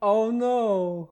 Add voiced sfx
ohno4.ogg